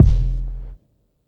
808s
gdhj_subkick.wav